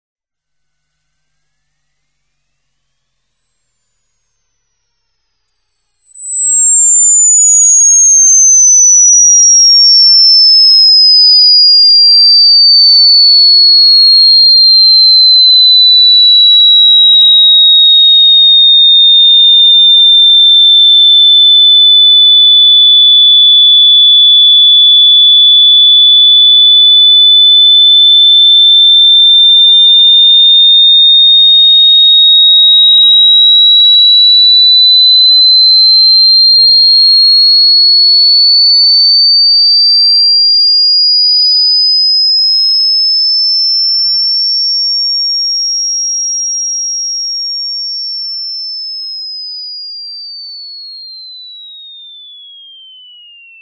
В коллекции представлены ультразвуковые и высокочастотные сигналы, неприятные для грызунов.
Ультразвук, который избавляет от мышей